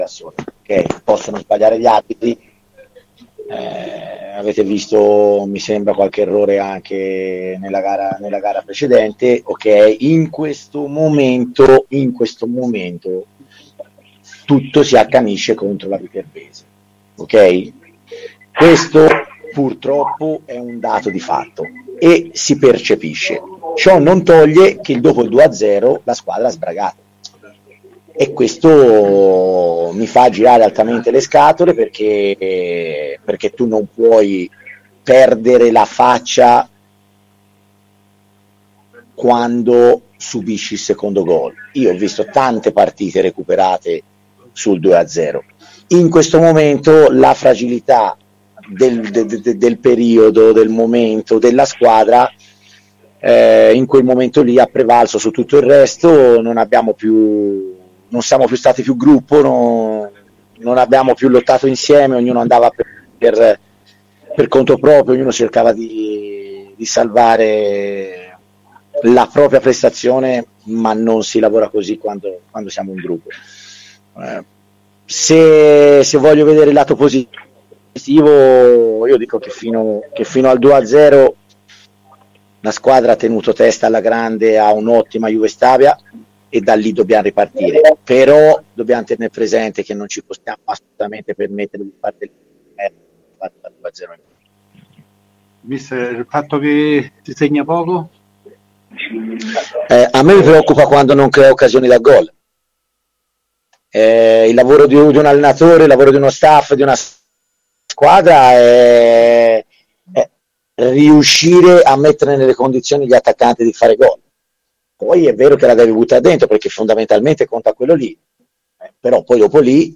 in conferenza stampa nel dopo partita